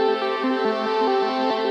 SaS_MovingPad03_140-A.wav